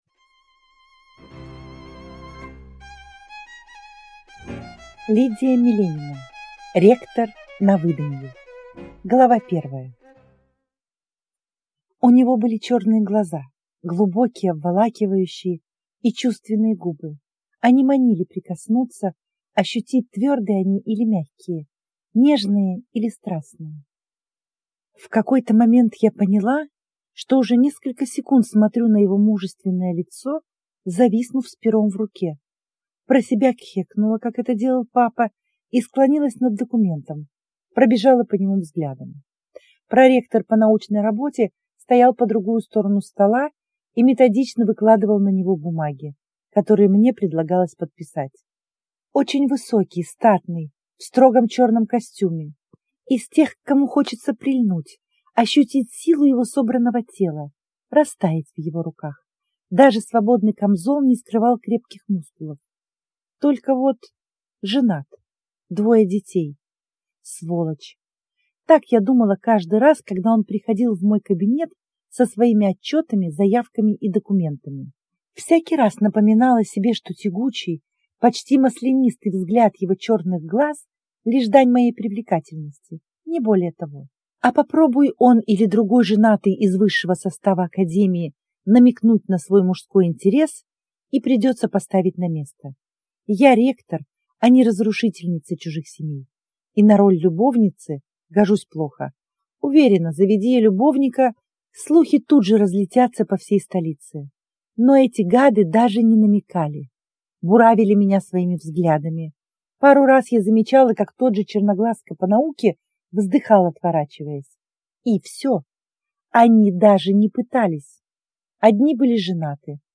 Аудиокнига Ректор на выданье | Библиотека аудиокниг
Прослушать и бесплатно скачать фрагмент аудиокниги